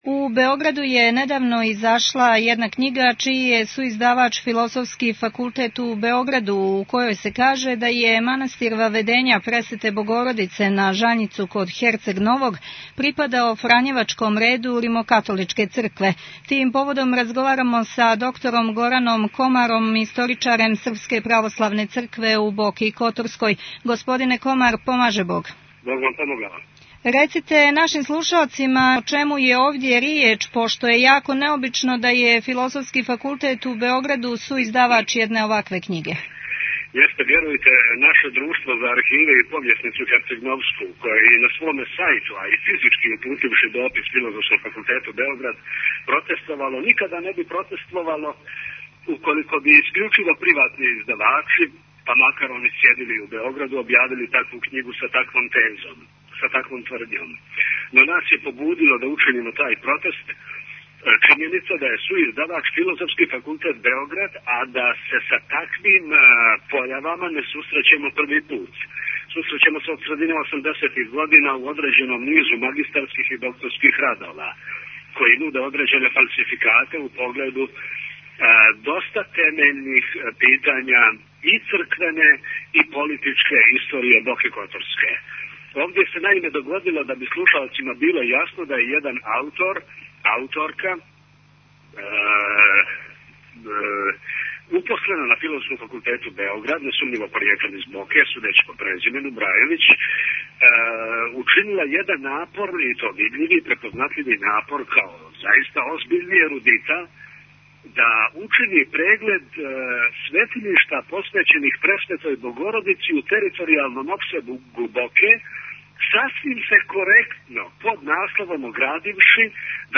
Актуелни разговори